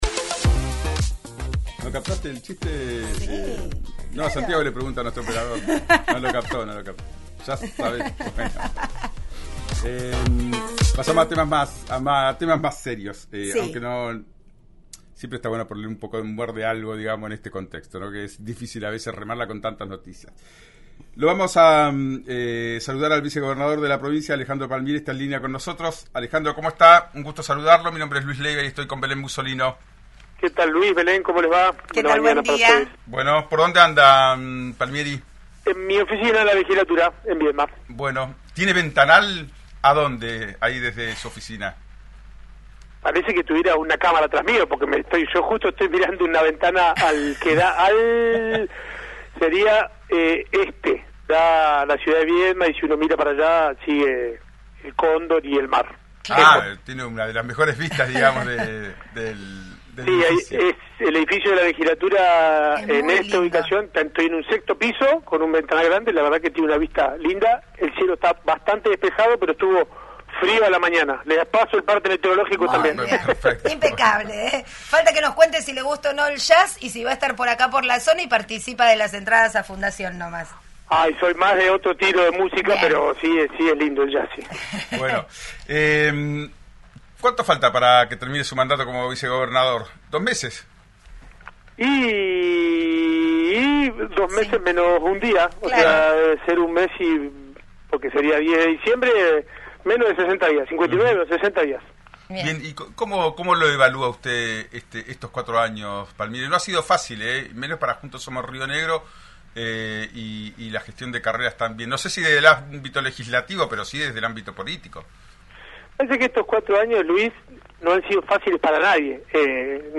En una entrevista realizada en el programa «Ya es Tiempo» de RÍO NEGRO RADIO, el vicegobernador de la provincia de Río Negro, Alejandro Palmieri, compartió sus reflexiones sobre los desafíos de los últimos cuatro años y el futuro político de la región.